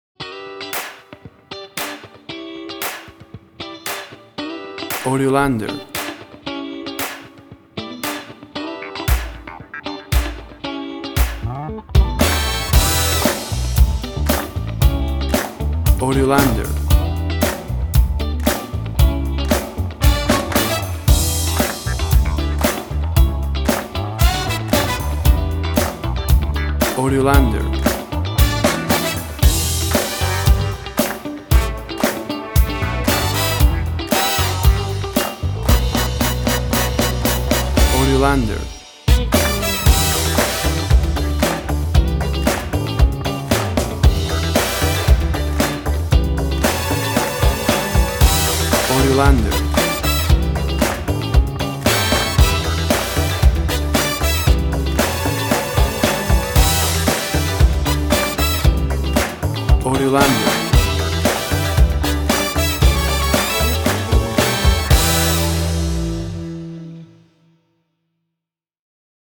Tempo (BPM): 115